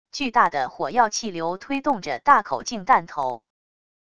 巨大的火药气流推动着大口径弹头wav音频